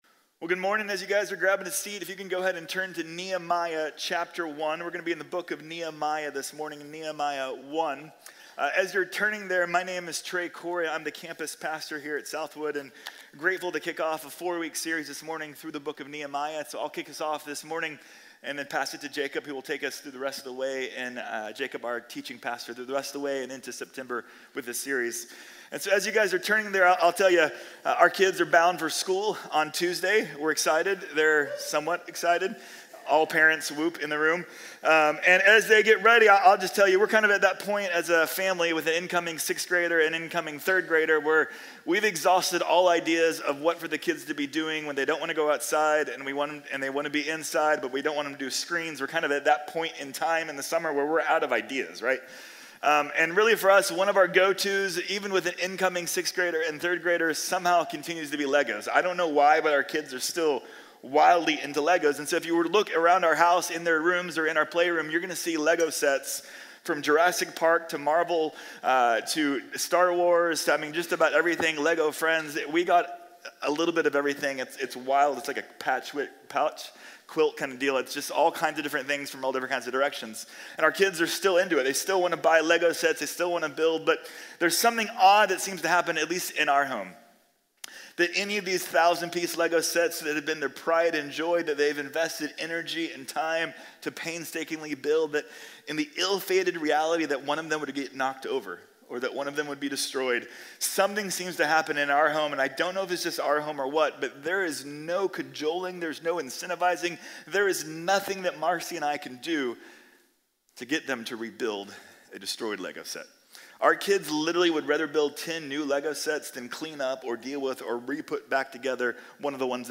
Nehemías: Una Oración | Sermón | Iglesia Bíblica de la Gracia